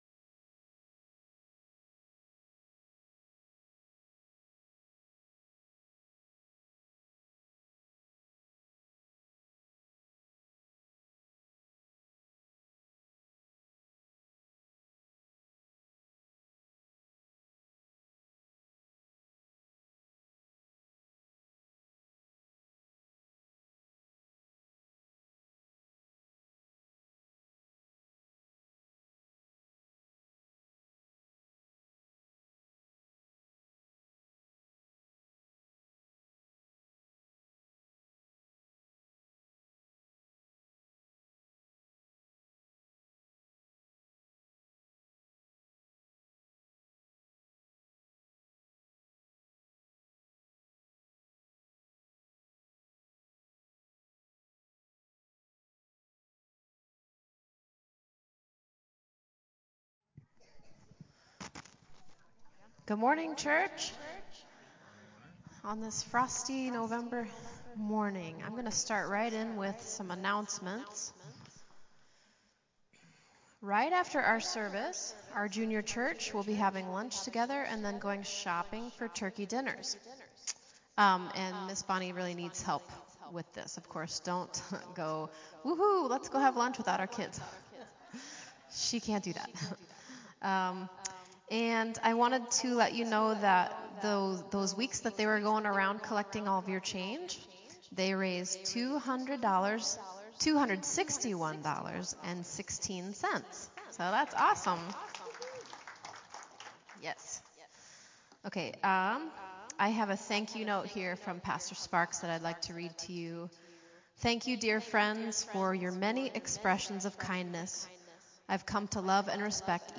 0:00 Announcements 5:18 Sermon Start
Refill-my-Nets-Sermon-Audio-CD.mp3